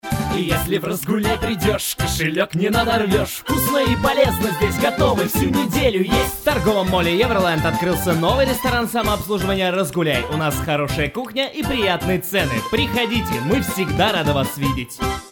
Голос универсальный гибкий!
Микрофон(Октава МЛ-53)---Предуселитель (ART Tube MP Project Series)----Аудиокарта(ESI U24XL)----Компьютер(Macbook Pro)
Демо-запись №1 Скачать